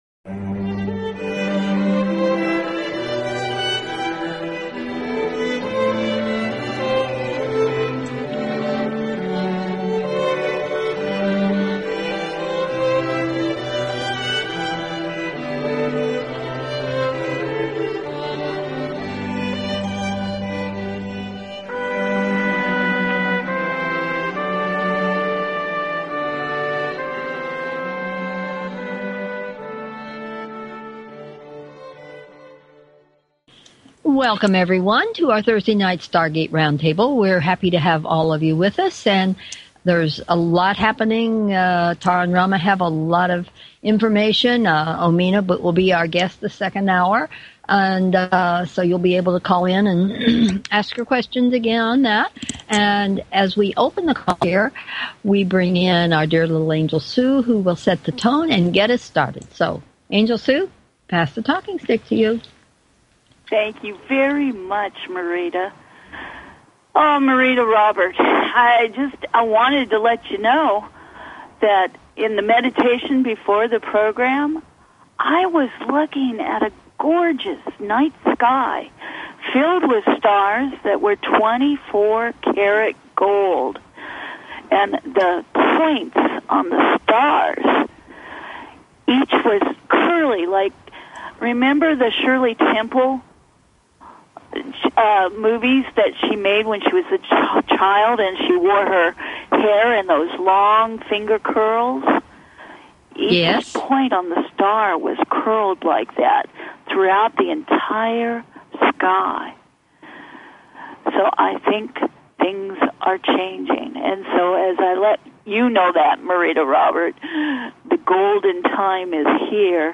Talk Show Episode, Audio Podcast, Stargate Round Table and Guest